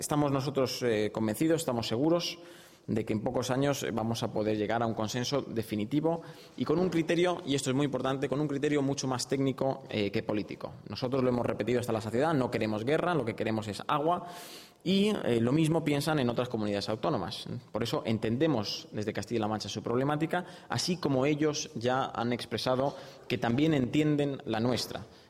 Portavoz del Gobierno: declaraciones trasvase río Tajo
portavoz_gobierno_trasvase_2.mp3